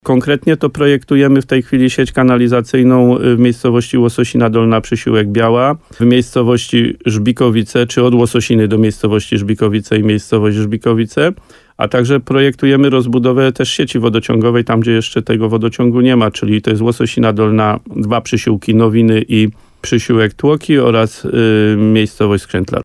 Wójt gminy Łososina Dolna z absolutorium i wotum zaufania za 2024 rok. Adam Wolak w programie Słowo za słowo na antenie RDN Nowy Sącz podkreślał, że to wynik nie tylko jego pracy, ale też radnych, sołtysów i pracowników gminy.